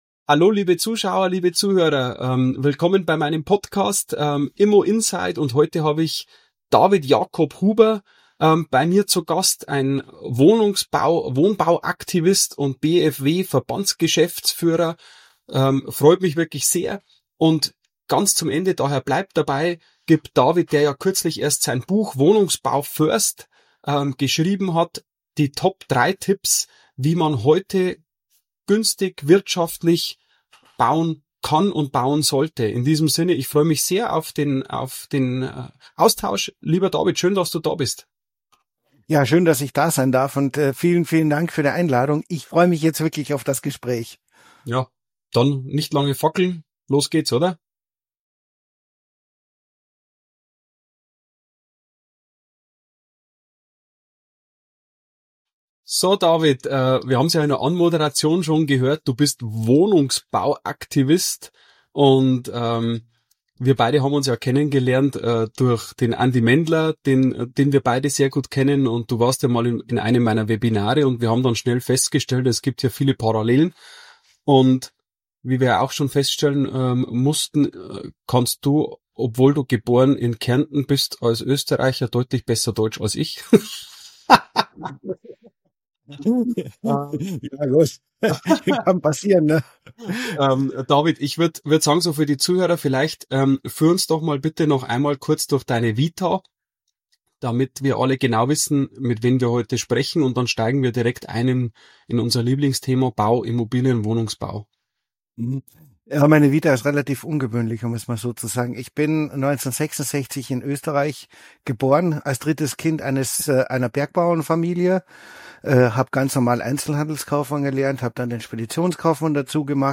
Was für ein Gespräch!